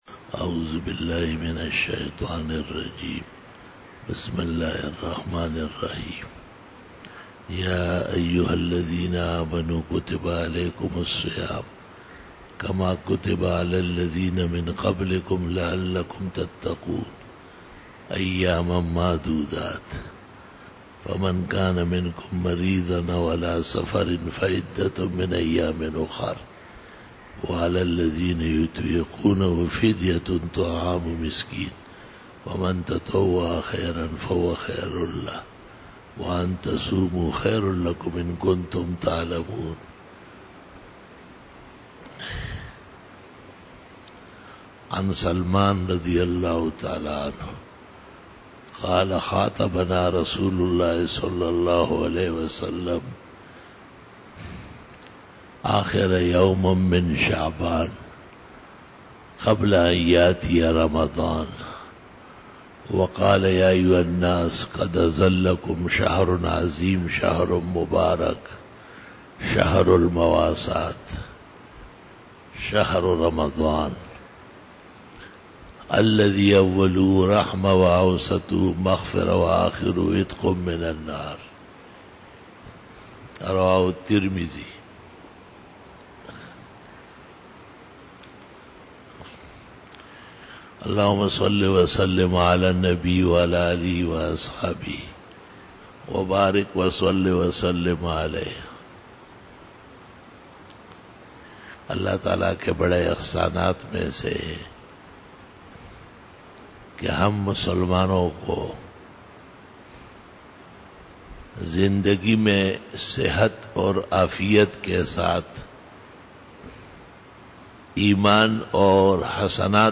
21 BAYAN E JUMA TUL MUBARAK 26 MAY 2017 (29 Shaban 1438H)
Khitab-e-Jummah